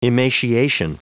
Prononciation du mot emaciation en anglais (fichier audio)
Prononciation du mot : emaciation